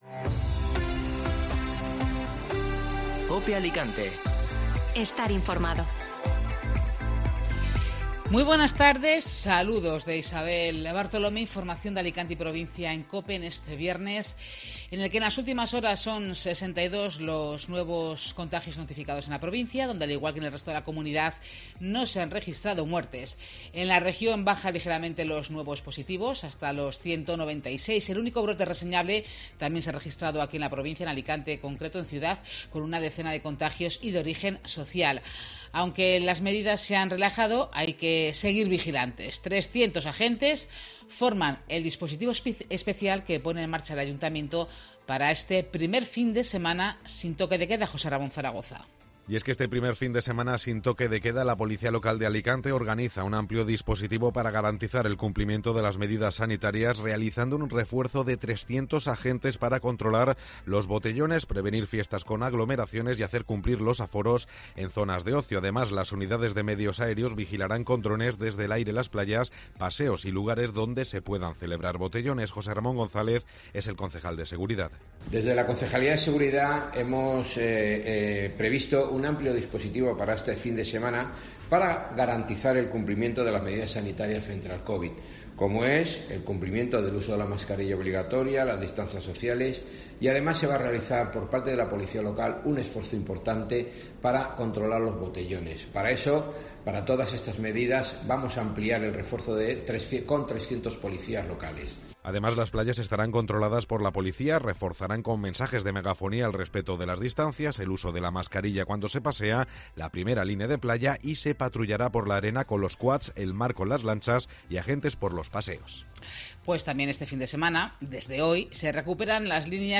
Informativo Mediodía COPE (Viernes 11 de junio)